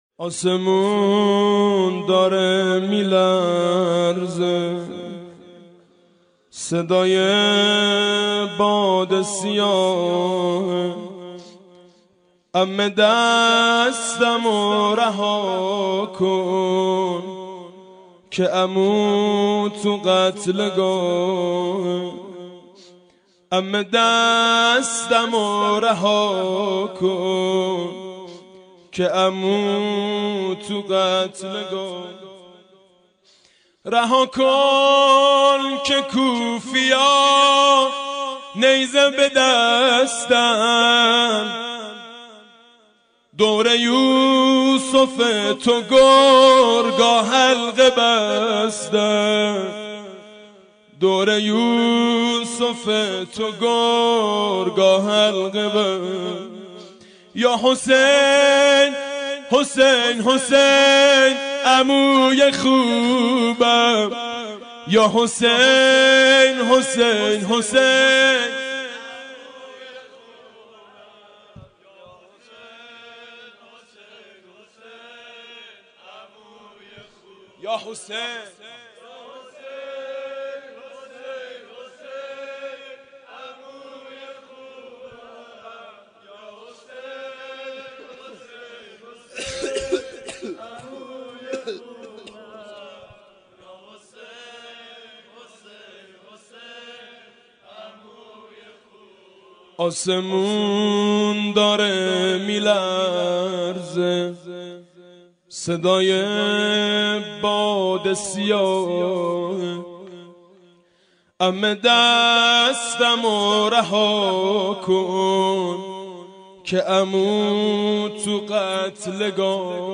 مداحی و سینه زنی «عموی خوبم»